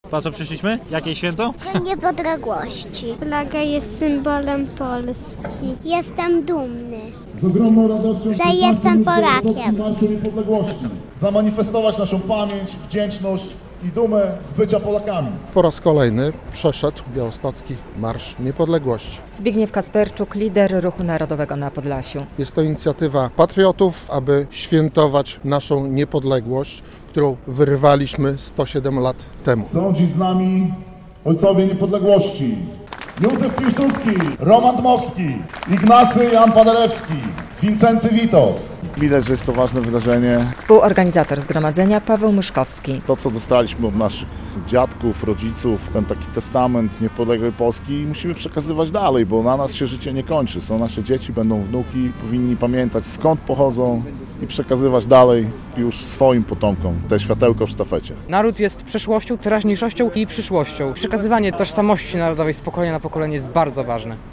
Były inscenizacje historyczne, przejście z pochodniami i śpiewanie pieśni patriotycznych. Po raz 15. zorganizowano w Białymstoku Marsz Niepodległości.